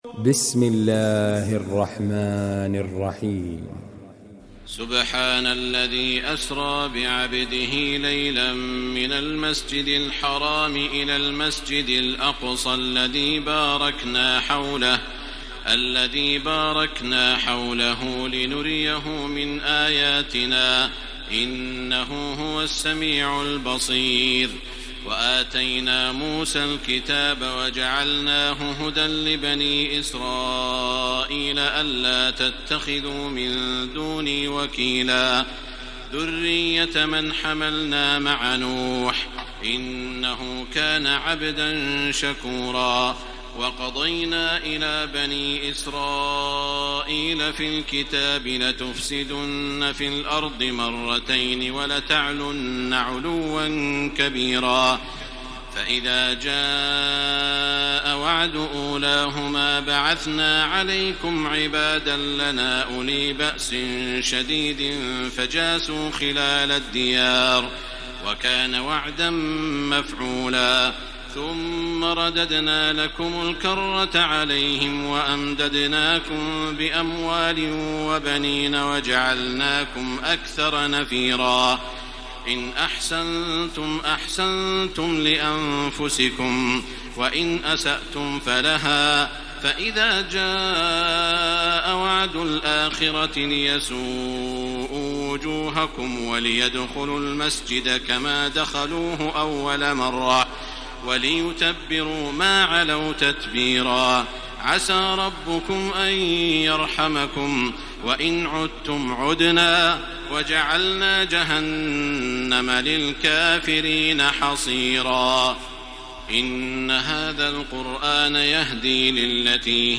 تراويح الليلة الرابعة عشر رمضان 1433هـ من سورة الإسراء (1-96) Taraweeh 14 st night Ramadan 1433H from Surah Al-Israa > تراويح الحرم المكي عام 1433 🕋 > التراويح - تلاوات الحرمين